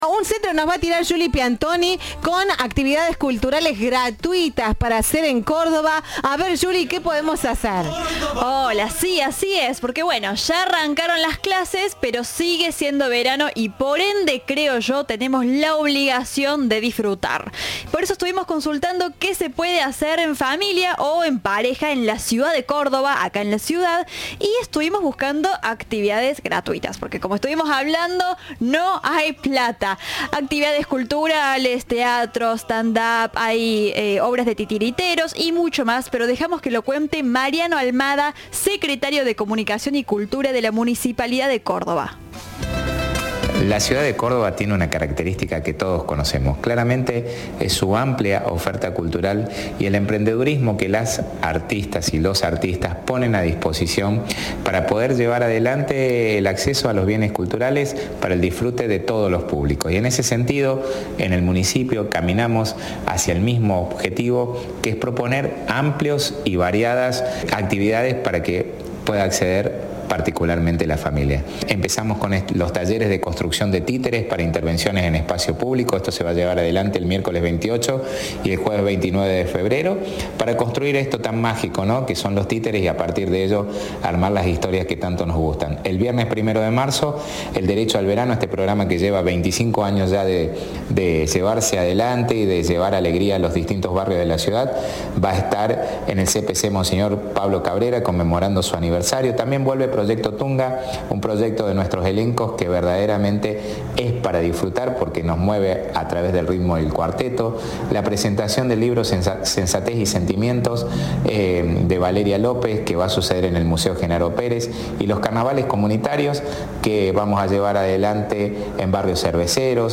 Mariano Almada, secretario de Cultura de la Municipalidad de Córdoba, dialogó con Cadena 3 y valoró una lista extensa de lugares para visitar y disfrutar en familia.